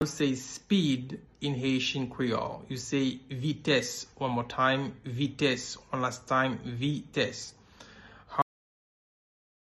Pronunciation:
28.How-to-say-Speed-in-Haitian-Creole-–-Vites-pronunciation-.mp3